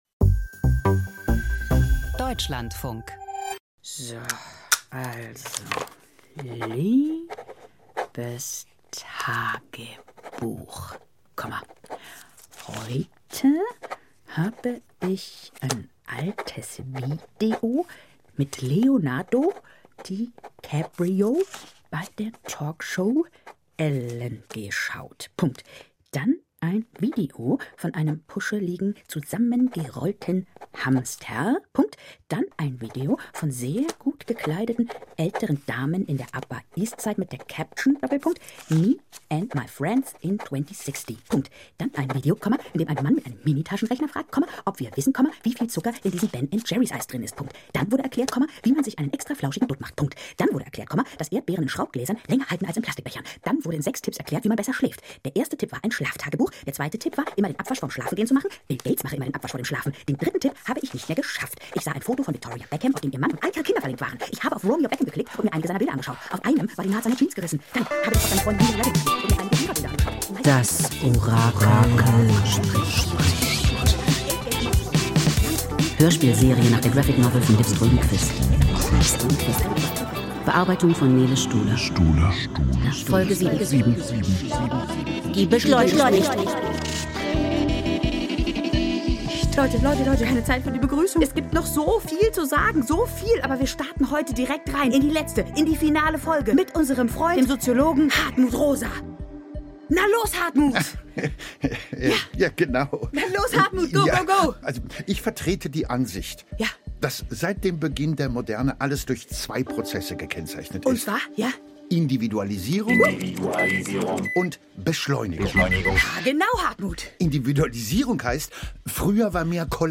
Das Orakel spricht – Hörspielserie nach Liv Strömquist